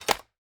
Free Fantasy SFX Pack
Chopping and Mining
chop 4.wav